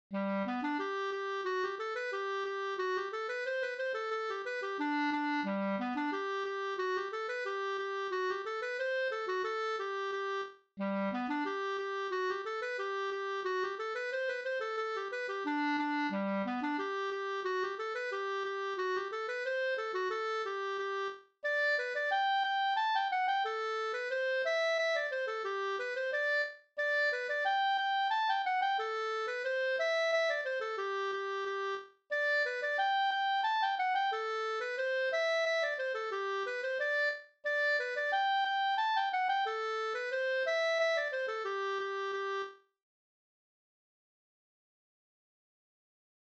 604 Engelska från Släp efter Fritz Oskar Brogren 1860-1945, Hageryd, Släp, Halland, ofta spelad som musik till folkdansen "Åttamannadans från Ödsmål", ur Svenska låtar, Bohuslän och Halland, nr 152,